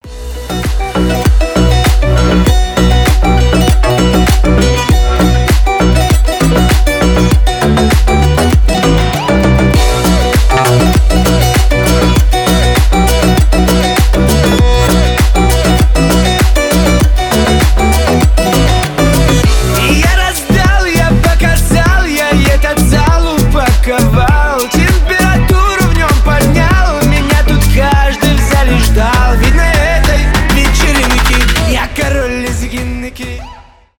лезгинка
танцевальные